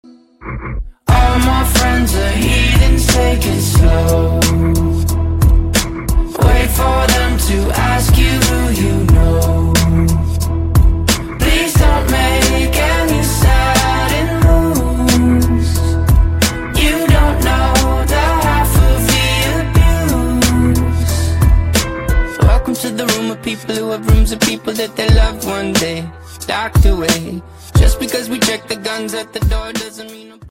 • Качество: 128, Stereo
Rap-rock
alternative
Бодрый саундтрек